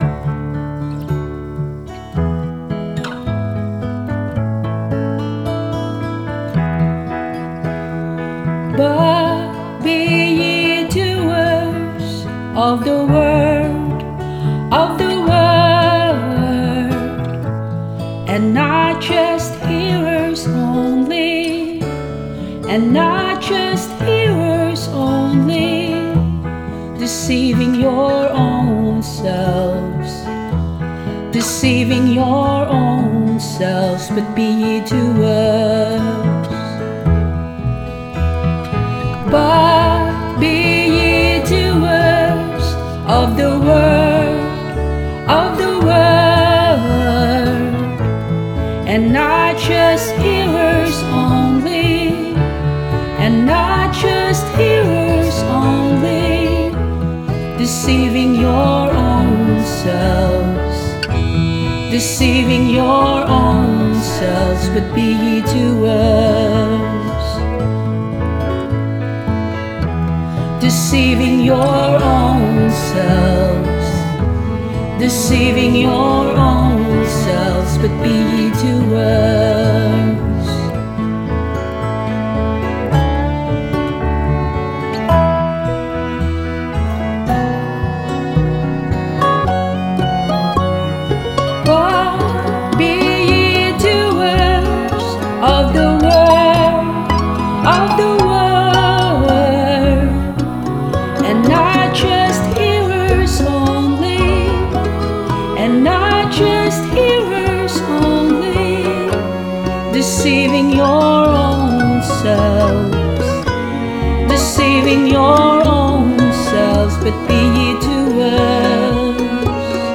Vocals and Band-In-A-Box arrangement